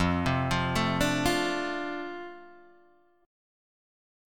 F6 chord